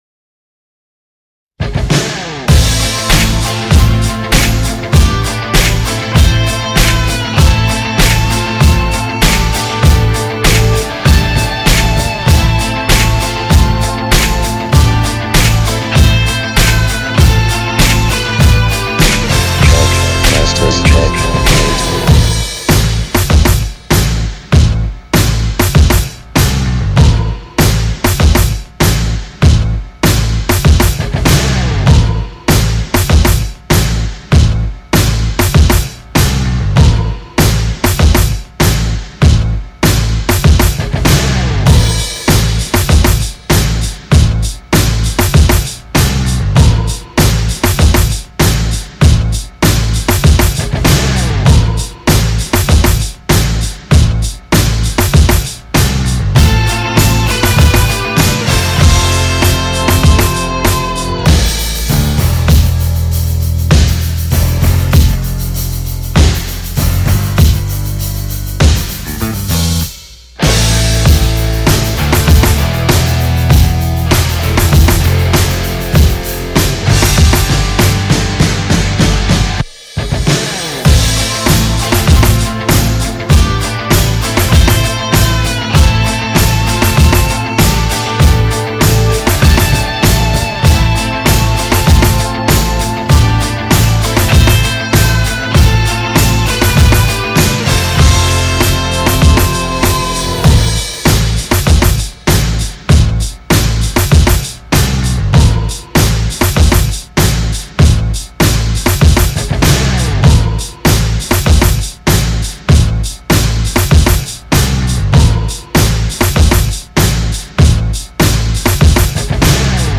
[inst.]프리보드의 '록키'게시물보고 바로 업로드ㅋ | 리드머 - 대한민국 힙합/알앤비 미디어